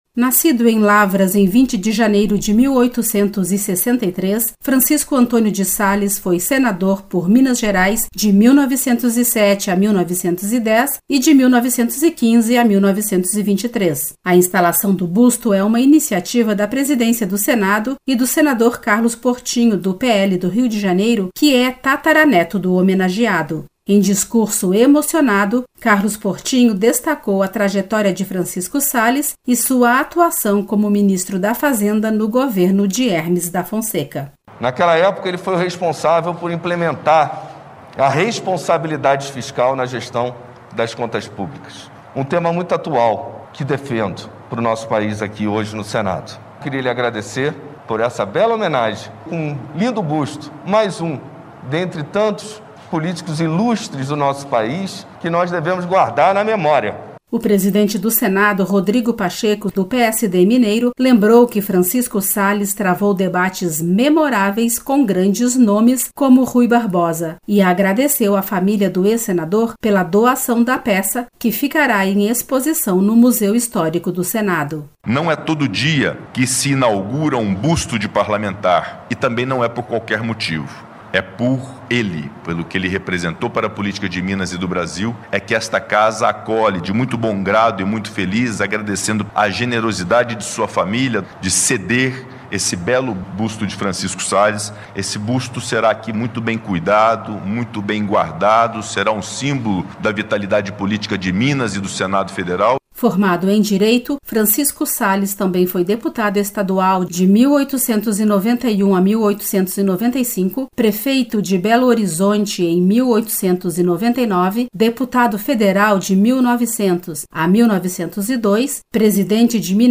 Solenidade
Em solenidade no Salão Nobre do Senado Federal, a Casa recebeu o busto do ex-senador Francisco Salles (1863-1933).